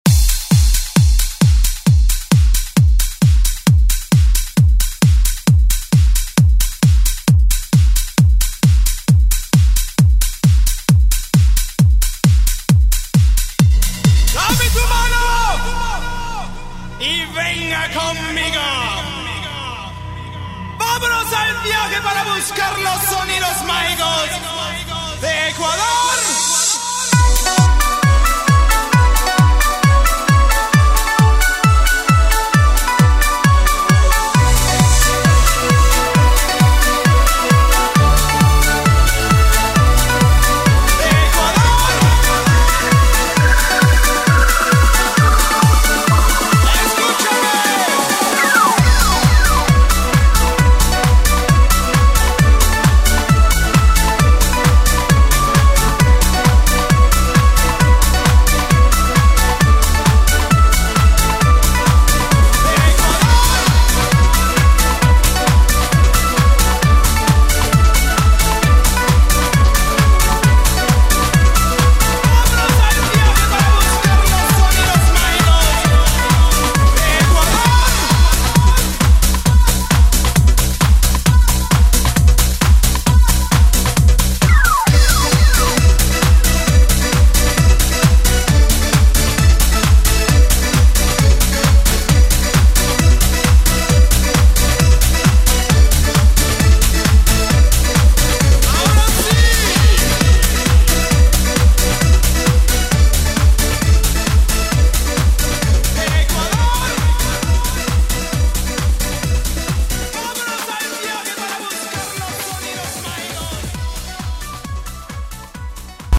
Alternative Rock Grunge Music
Extended Intro Outro
118 bpm
Genre: 90's